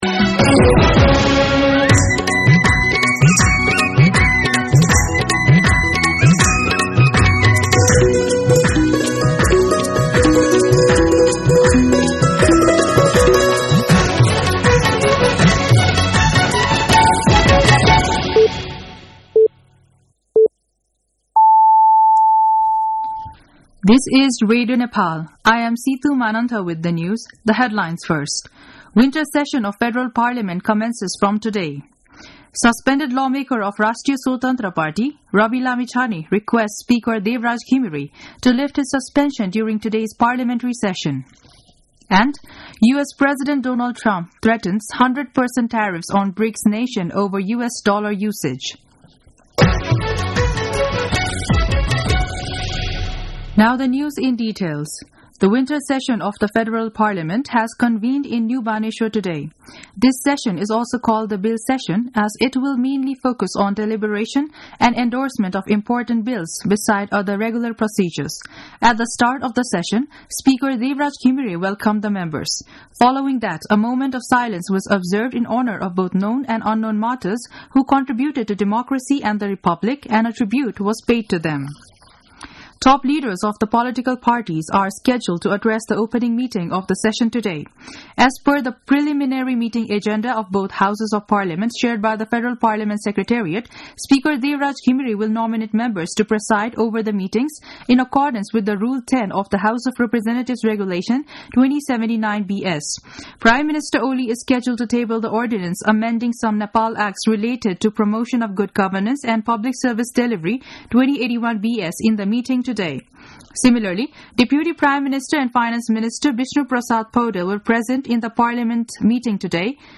दिउँसो २ बजेको अङ्ग्रेजी समाचार : १९ माघ , २०८१
2-pm-English-News-2.mp3